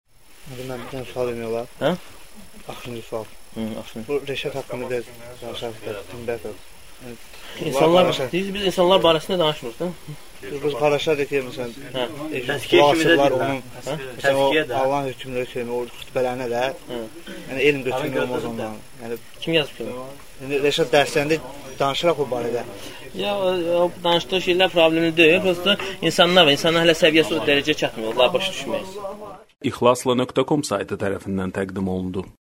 Dərslərdən alıntılar – 75 parça